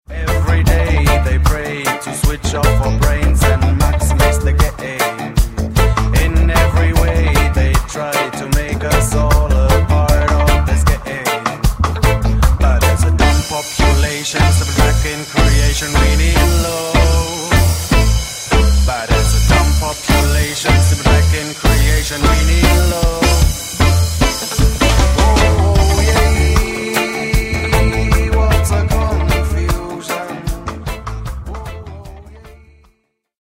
Band auch sehr überzeugend Steppaz spielen kann.